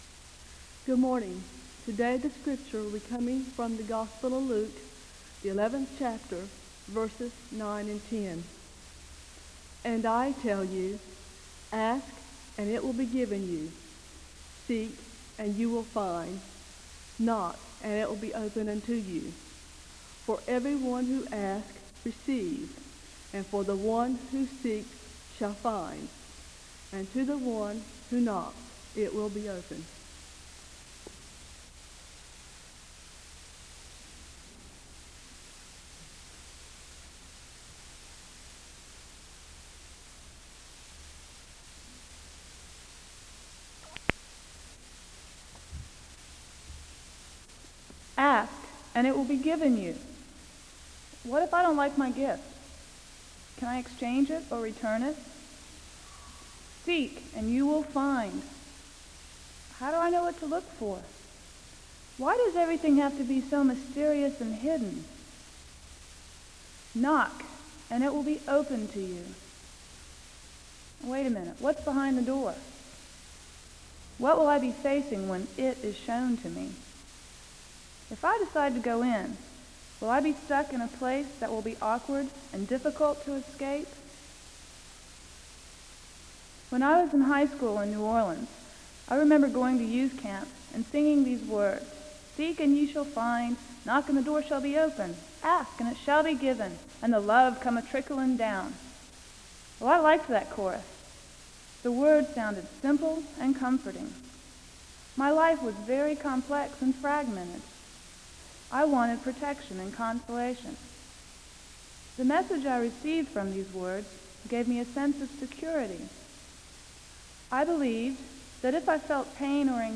The service begins with a Scripture reading from Luke 11:9-10 (00:00-00:44).
The audience is led in a song of worship (17:24-22:14). The service ends with a word of prayer (22:15-22:52).